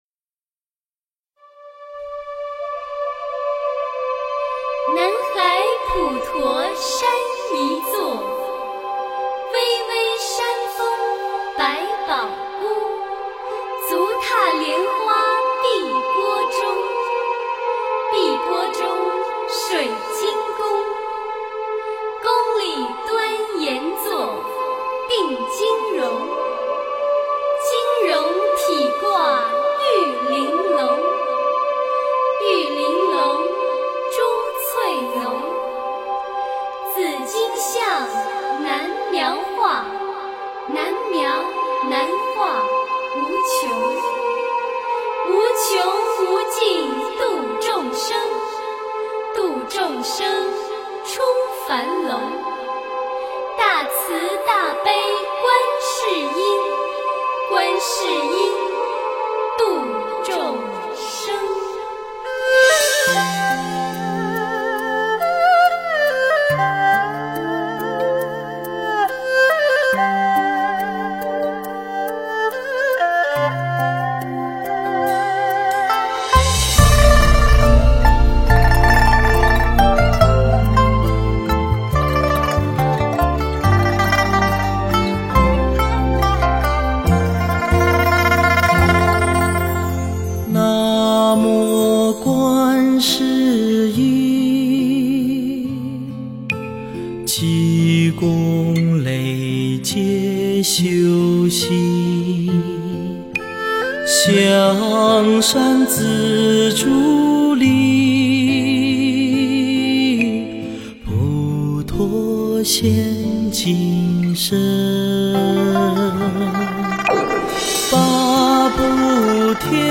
诵经
佛音 诵经 佛教音乐 返回列表 上一篇： 回向偈 下一篇： 人生一首歌 相关文章 地藏经(男声念诵）2-3 地藏经(男声念诵）2-3--未知...